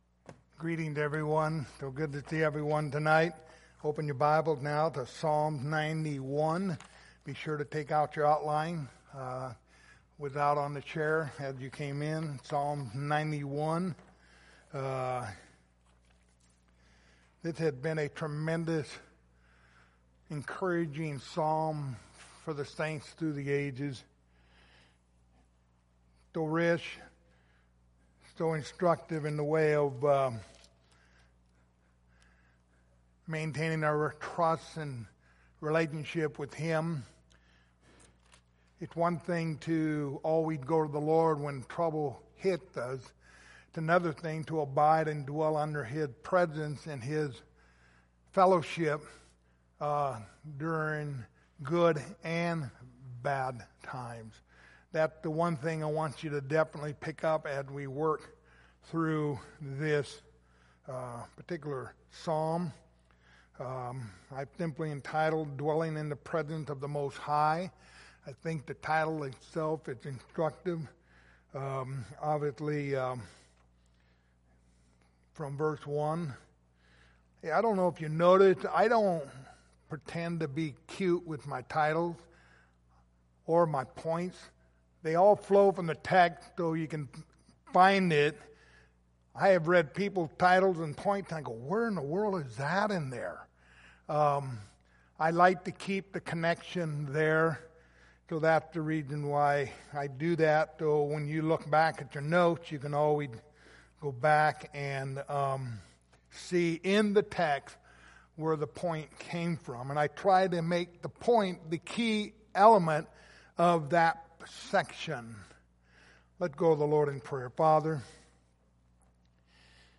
Passage: Psalms 91:1-16 Service Type: Sunday Evening